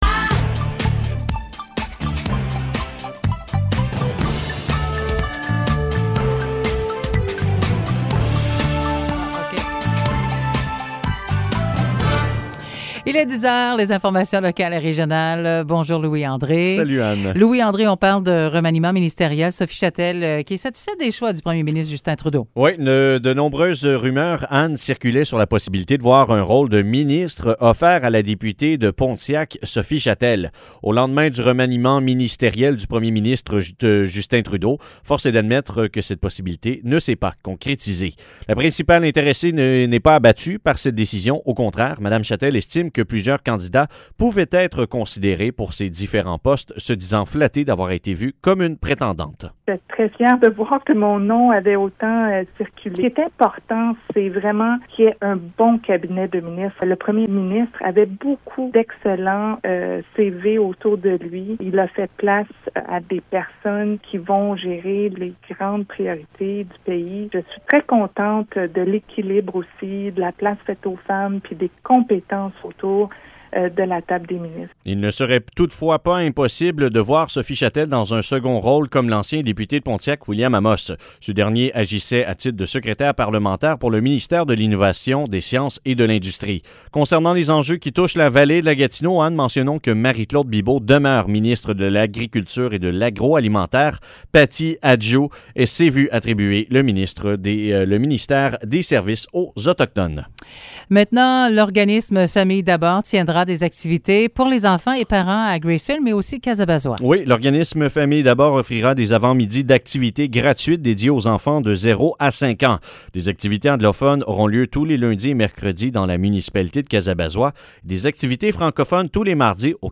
Nouvelles locales - 27 octobre 2021 - 10 h